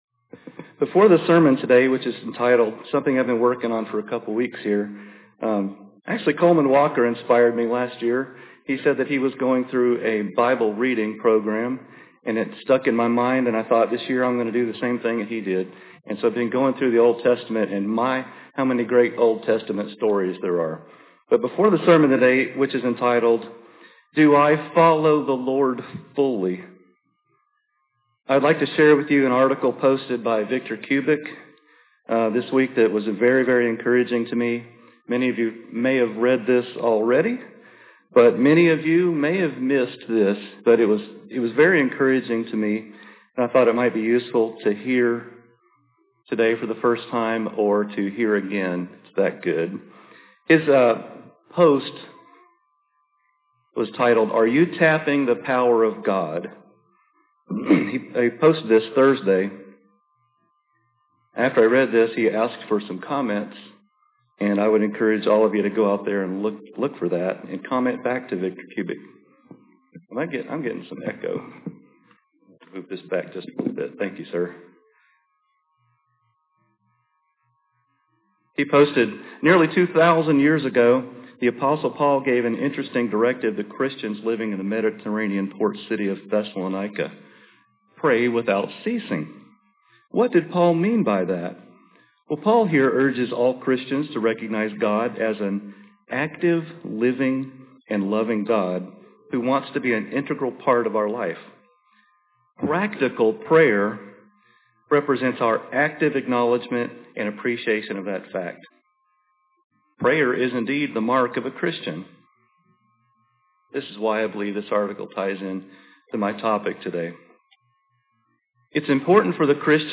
Given in Nashville, TN
Transforming Your Life: The Process of Conversion Making Life Work UCG Sermon Studying the bible?